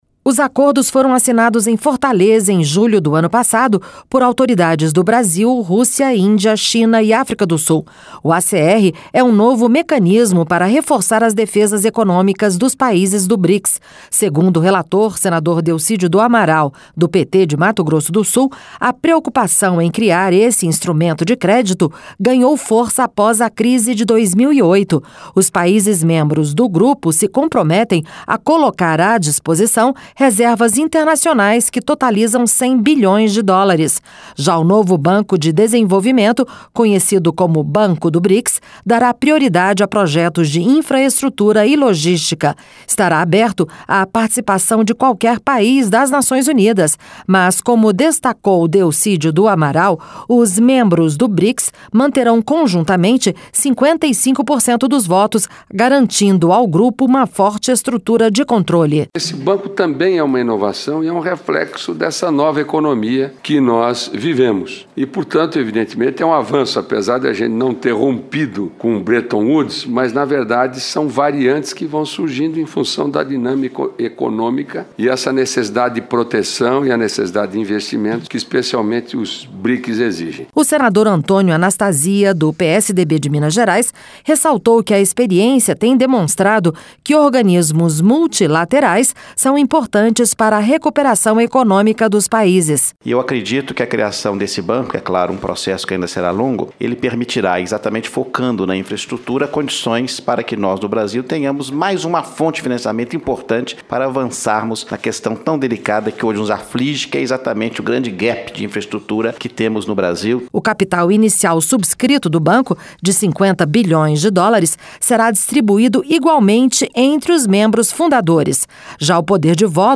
Para o senador Delcídio do Amaral (PT-MS), o Banco do Brics é uma inovação e reflexo dessa nova economia que vivemos. Reportagem